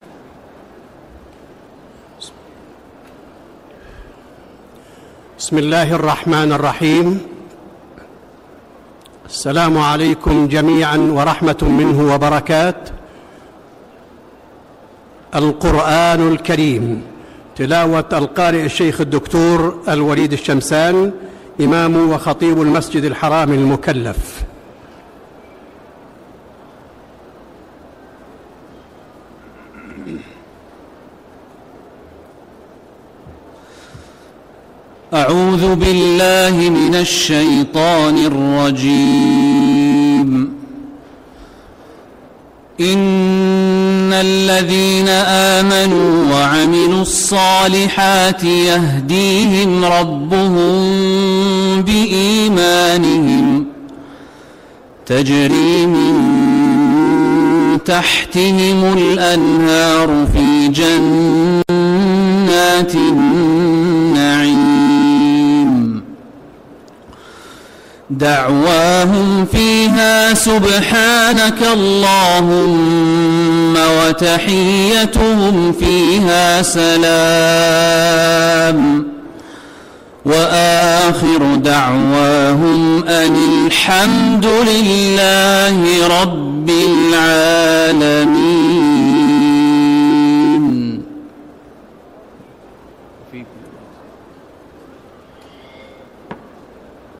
ندوة الفتوى في الحرمين الشريفين